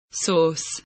source kelimesinin anlamı, resimli anlatımı ve sesli okunuşu